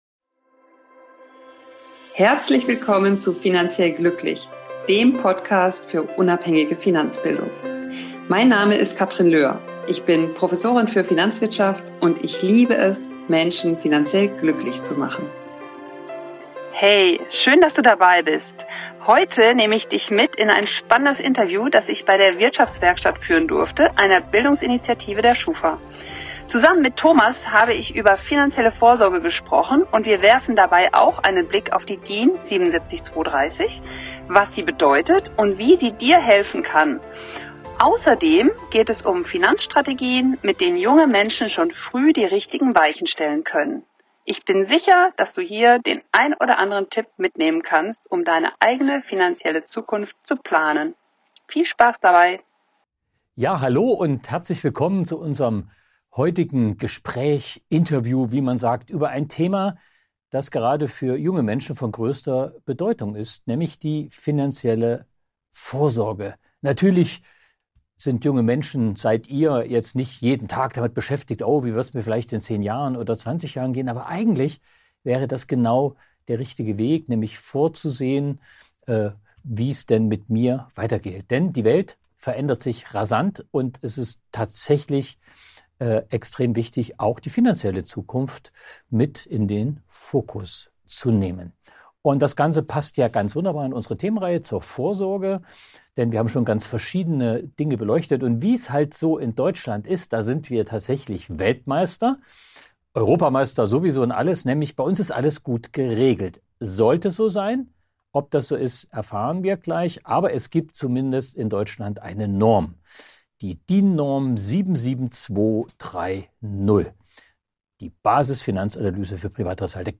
Interview zur finanziellen Vorsorge ~ Finanziell glücklich. Podcast